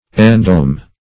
Endome \En*dome"\, v. t. To cover as with a dome.